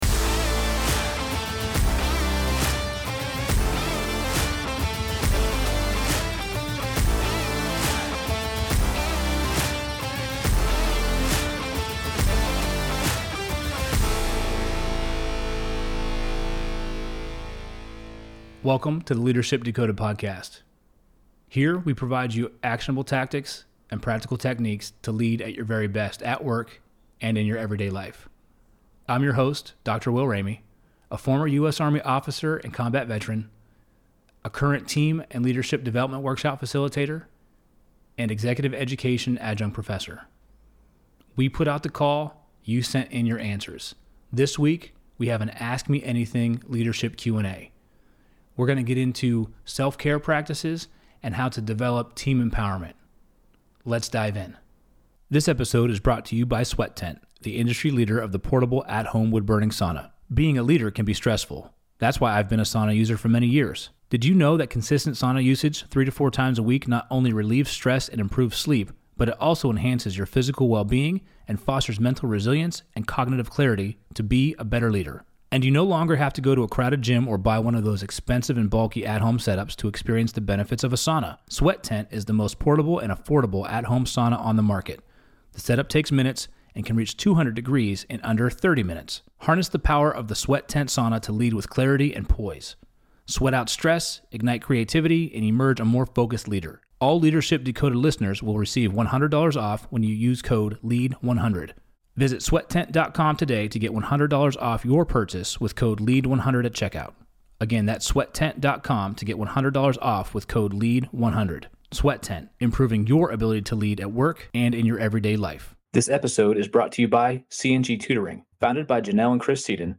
Ask Me Anything Leadership Q&A | Ep.039